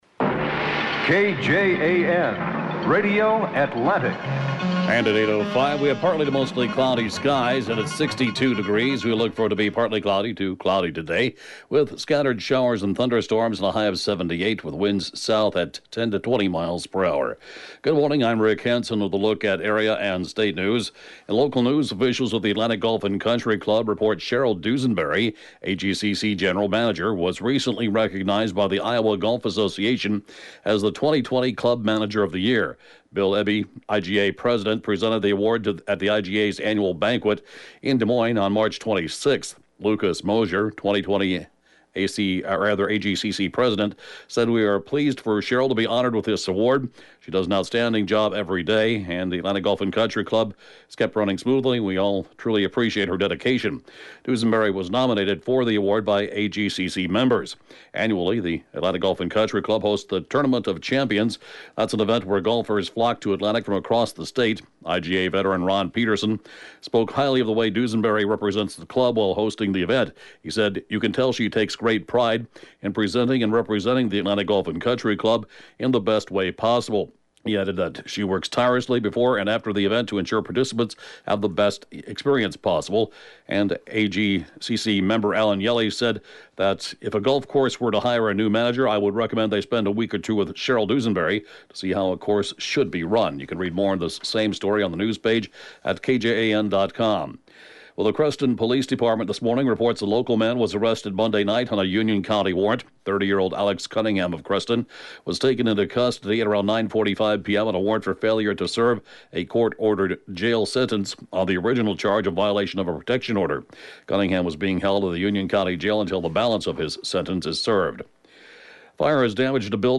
(Podcast) KJAN Morning News & Funeral report, 12/6/19